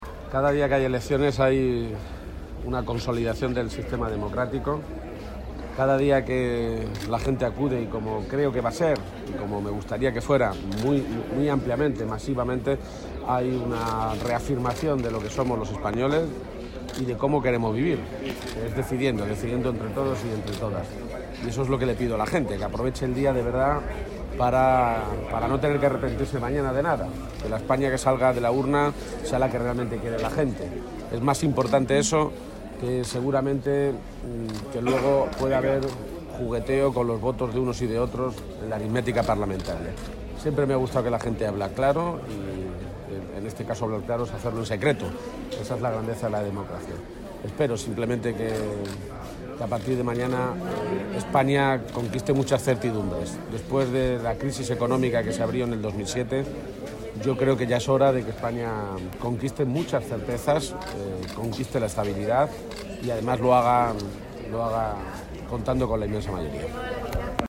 García-Page, que realizaba estas declaraciones tras ejercer su derecho al voto en el colegio público “Ciudad de Nara” de Toledo, señaló que después de la crisis económica que se abrió en el 2007 “ya ha llegado la hora de conquistar esas certezas y esa estabilidad”.
Cortes de audio de la rueda de prensa